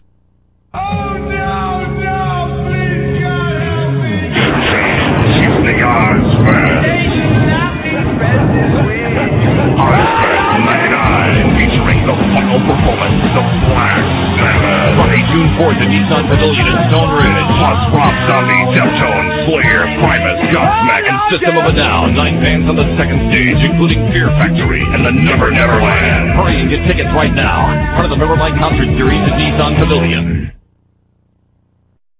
Ozzfest 1999 - Commercial / RealVideo   On Sale April 24th